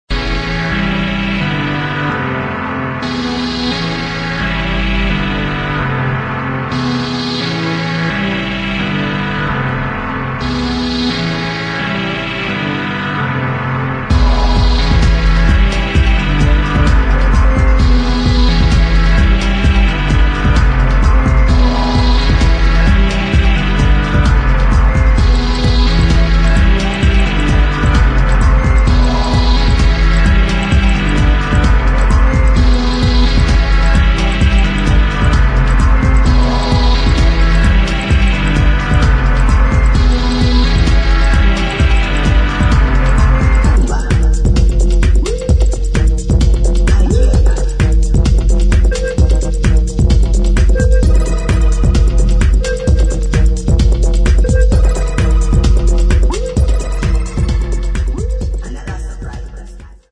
[ TECHNO ]
80年代末のブリープ・テクノを思わせるクラシック・スタイル・エレクトロ